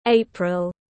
Tháng 4 tiếng anh gọi là april, phiên âm tiếng anh đọc là /ˈeɪ.prəl/
April /ˈeɪ.prəl/